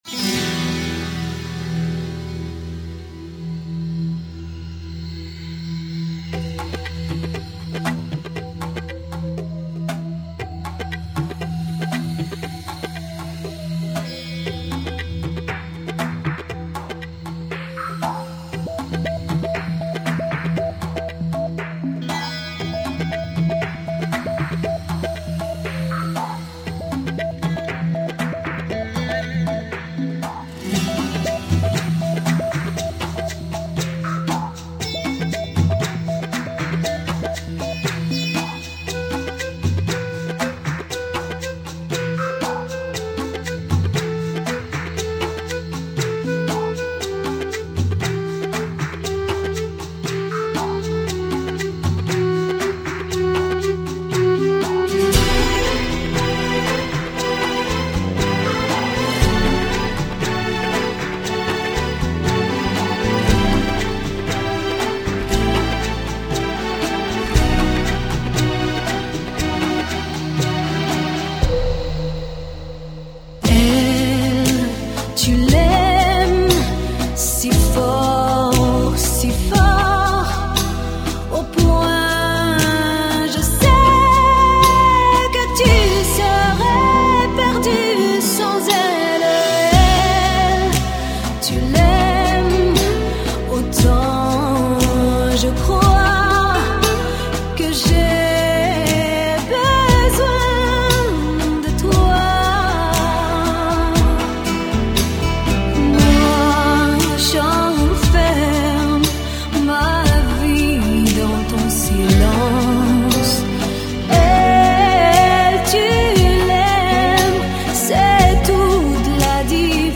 А знаешь, в ней ритм заводит. Дарбуки - это что-то.
А еще голос у нее какой-то магический.
Там спочатку звучать дарбуки..Ритм...